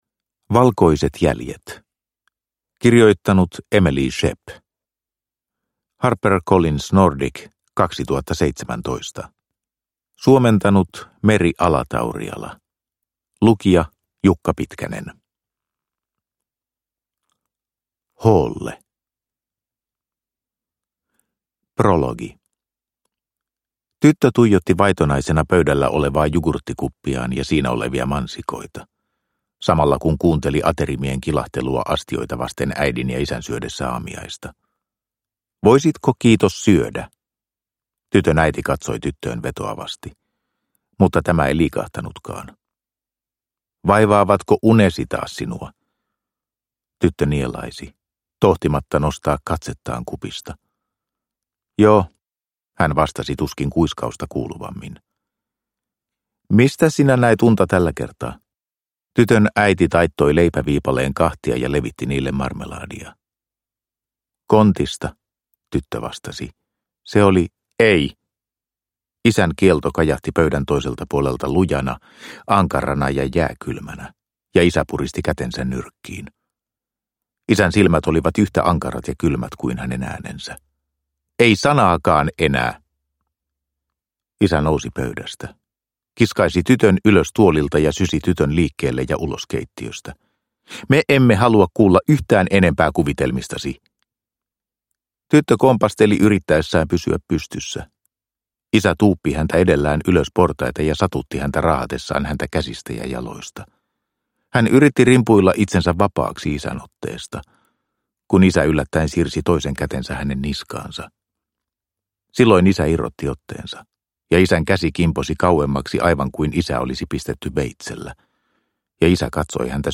Valkoiset jäljet – Ljudbok – Laddas ner